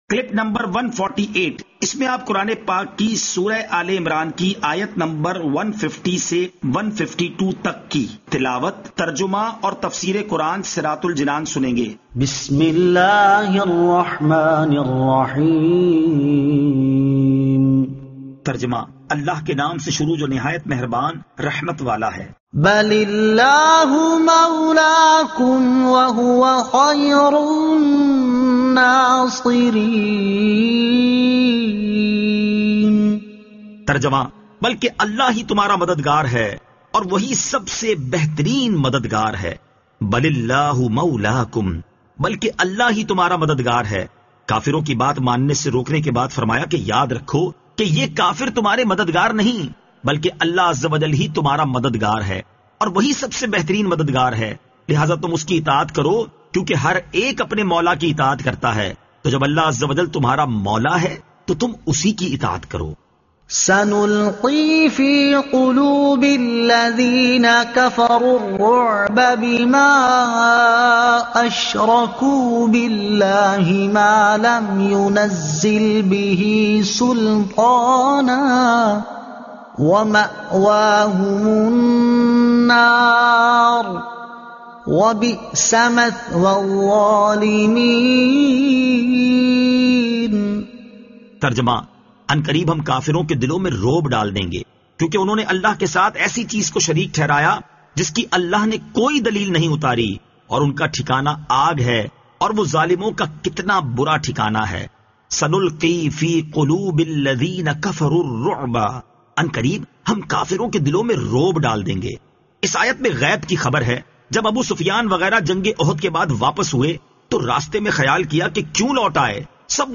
Surah Aal-e-Imran Ayat 150 To 152 Tilawat , Tarjuma , Tafseer